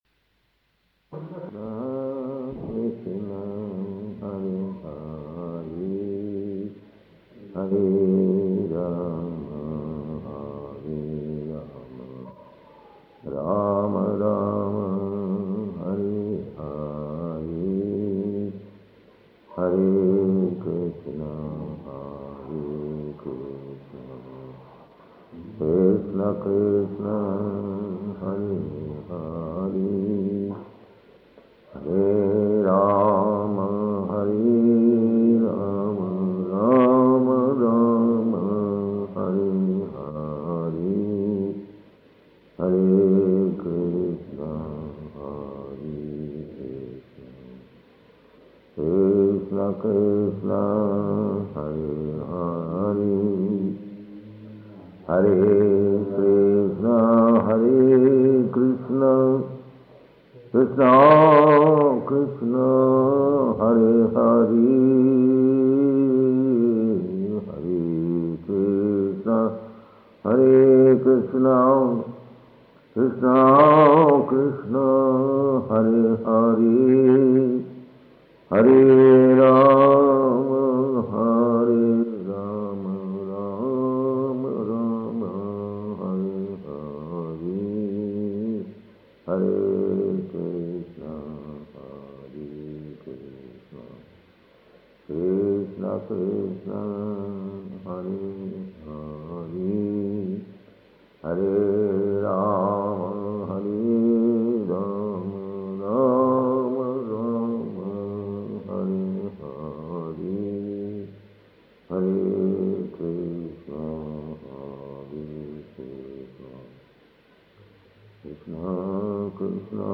Type: Srimad-Bhagavatam
Location: Bombay